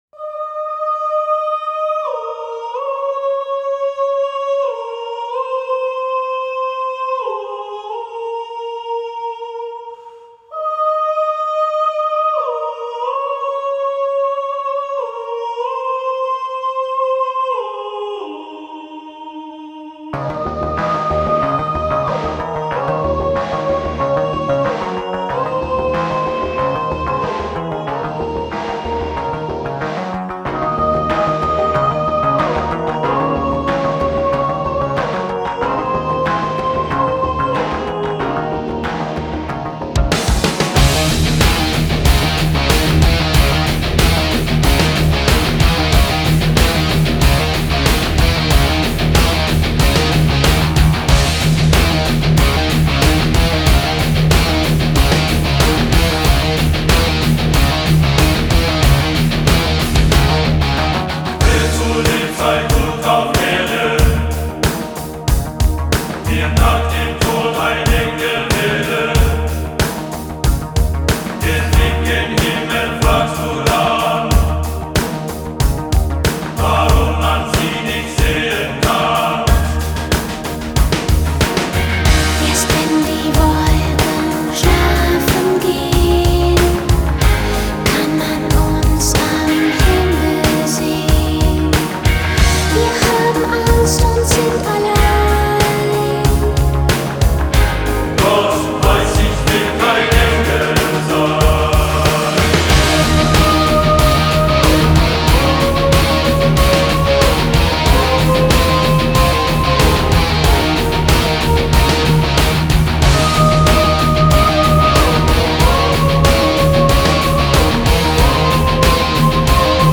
Genre: Pop, Chants